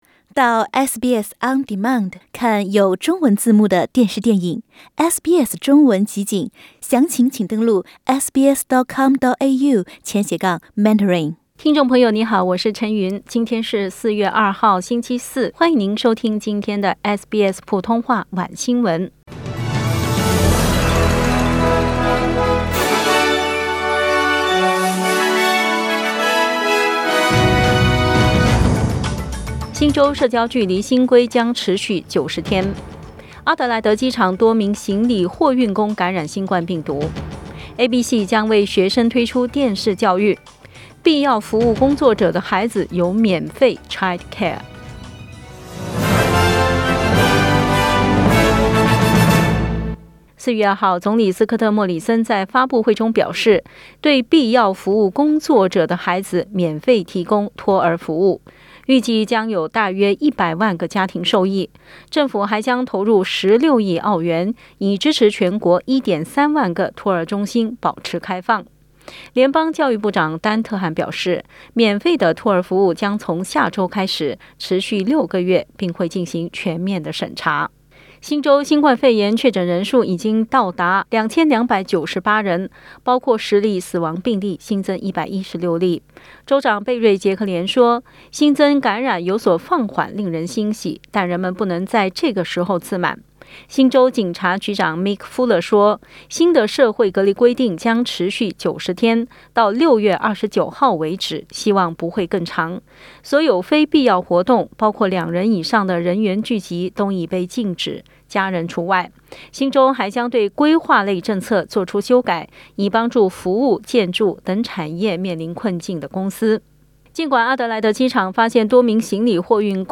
SBS晚新闻（4月2日）